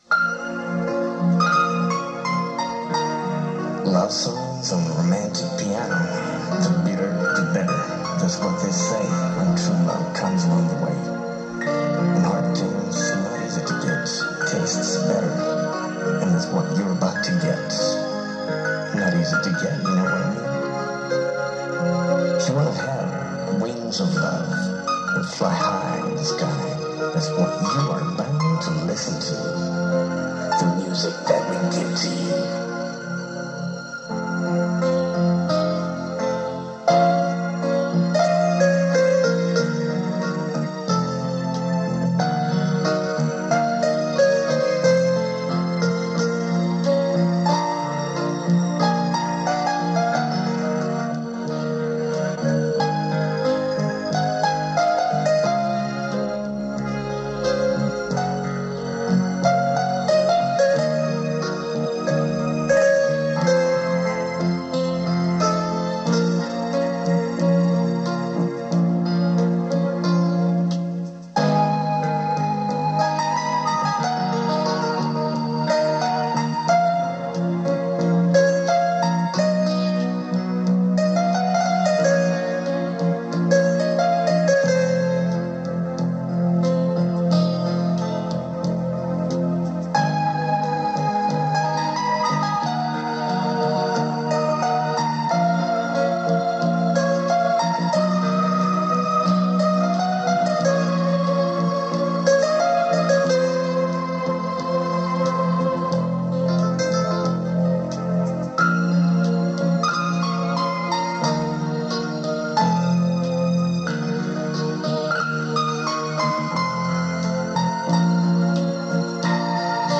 They all come from various audio cassettes.